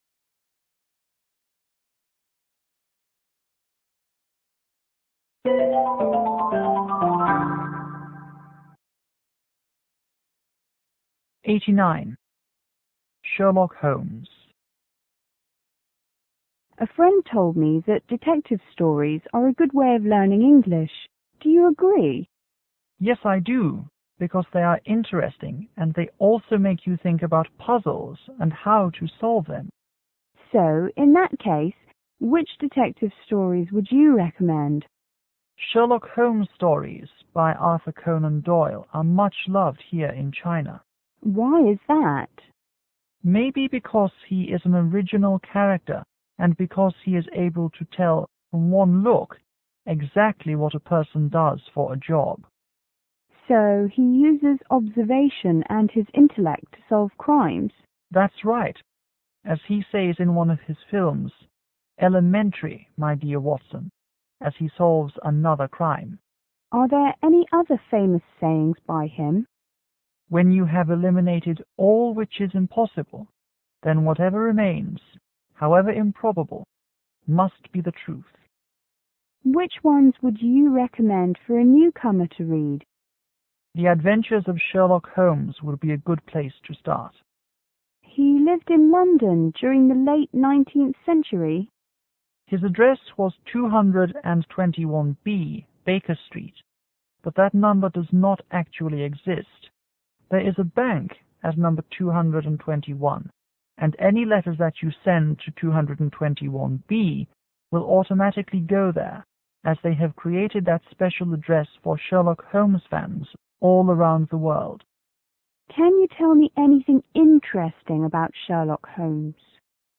C: Chinese student               E:  English teacher